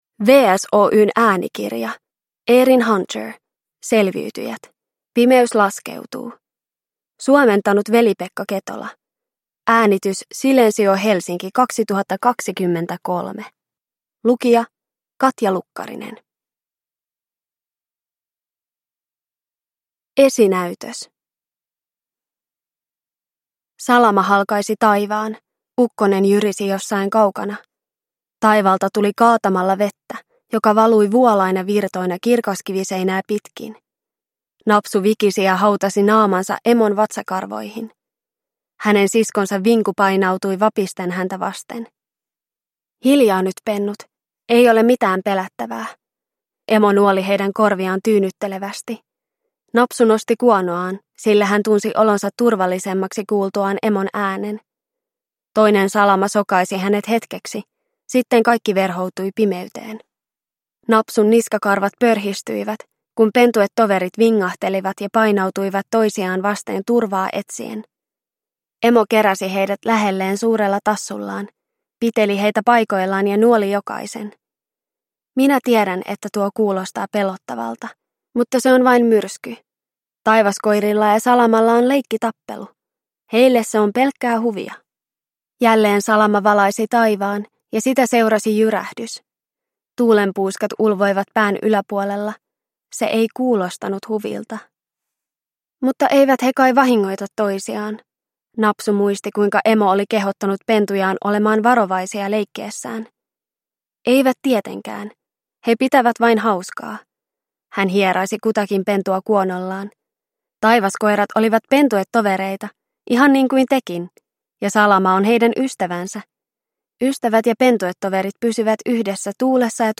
Selviytyjät: Pimeys laskeutuu – Ljudbok